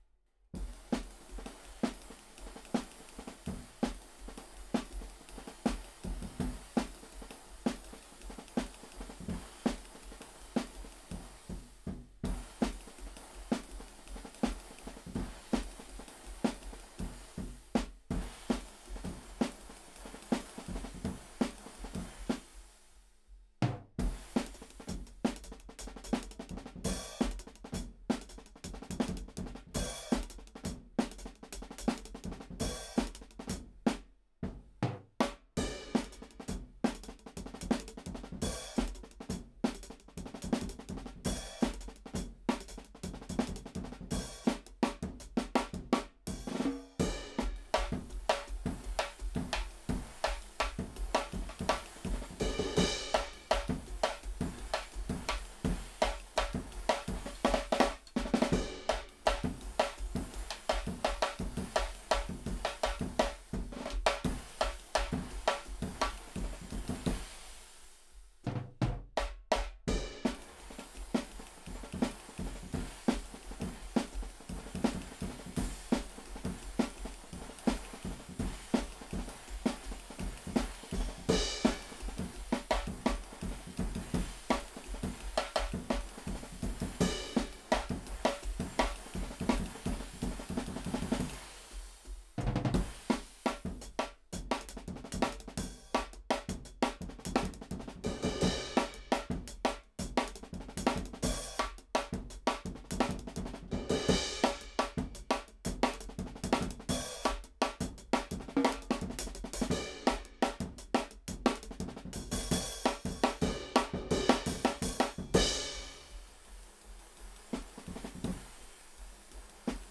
Index of /4 DRUM N BASS:JUNGLE BEATS/BEATS OF THE JUNGLE THAT ARE ANTIFUNGAL!!/RAW MULTITRACKS
OH HH_1.wav